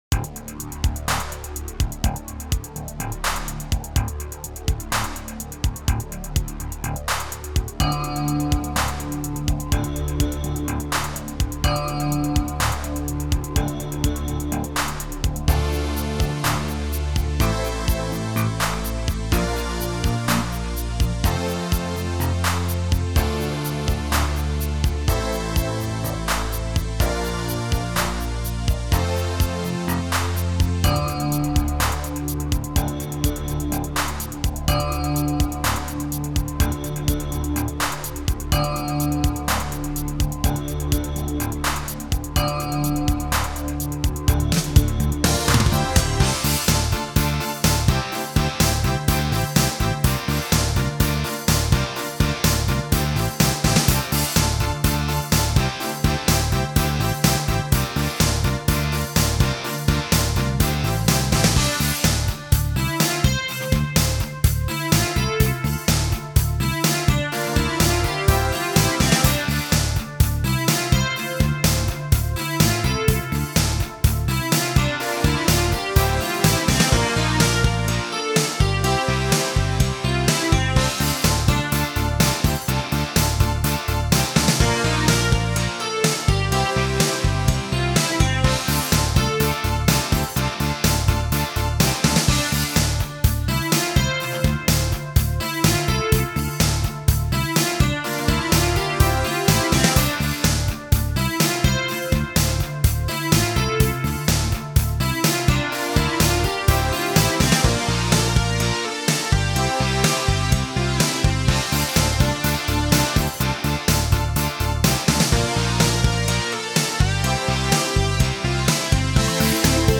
This is a remix